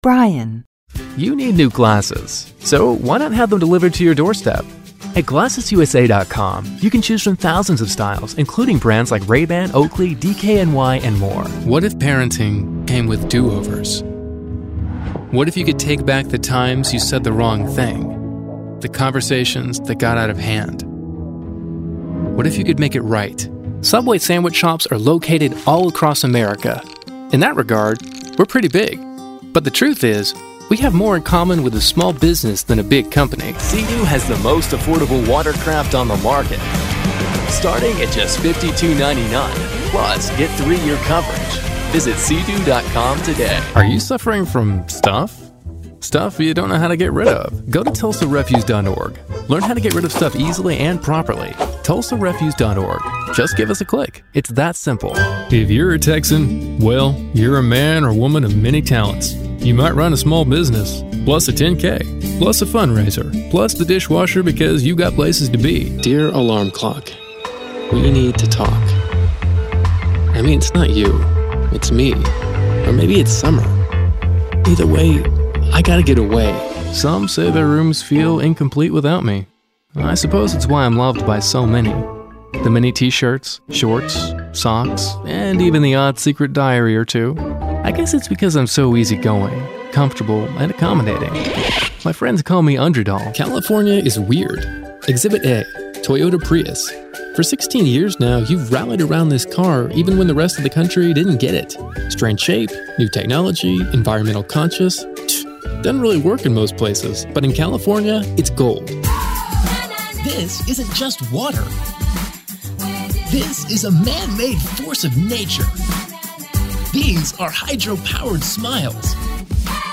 Showcase Demo
anti-announcer, conversational, friendly, genuine, homespun, millennial, promo
conversational, cool, guy-next-door, mellow, millennial, sincere
character, cocky, conversational, humorous, smooth
announcer, promo, upbeat
anti-announcer, compelling, confident, friendly, guy-next-door, millennial, promo